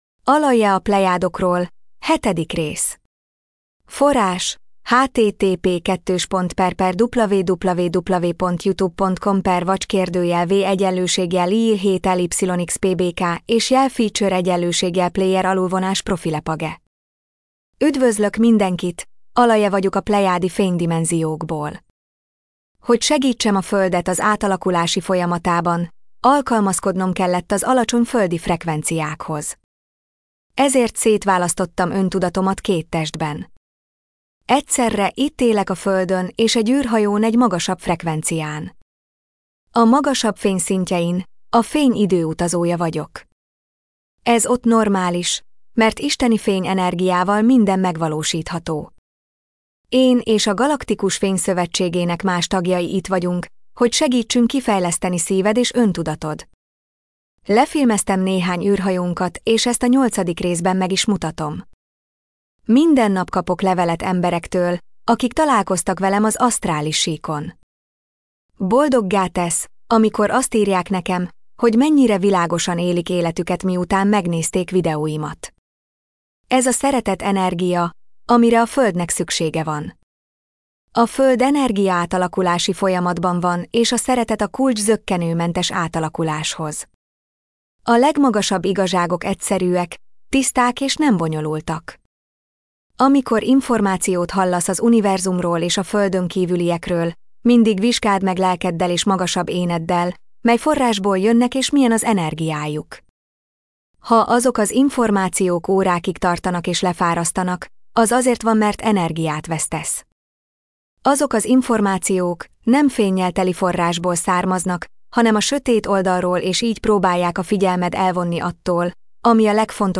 MP3 gépi felolvasás